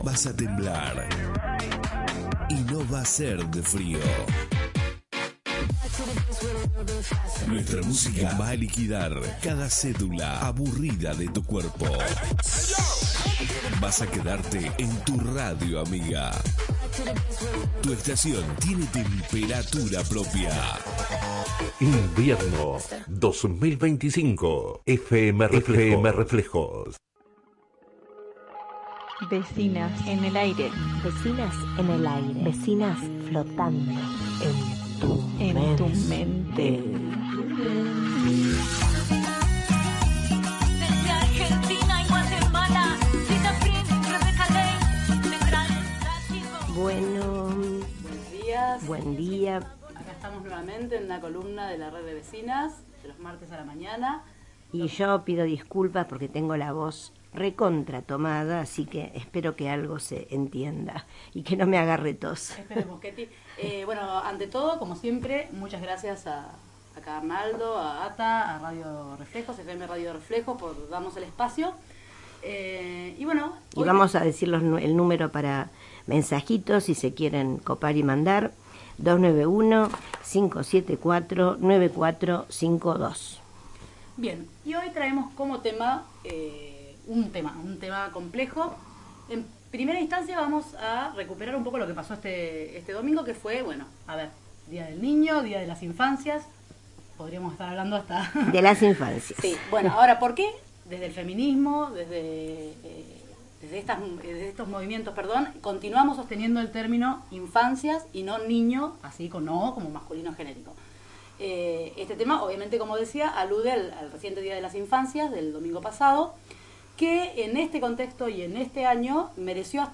A propósito del día de las infancias, tocamos un tema difícil, tal como es el retroceso que vemos en torno a las madres protectoras y a las y los profesionales que acompañan a victimas de abuso sexual. Se está tratando de instalar el tema de las “falsas denuncias”, que no llegan al 3%, cuando en realidad el abuso sexual en la infancia tiene cifras alarmantes. Entrevistamos para esto a uno de los mayores especialistas argentinos en la materia.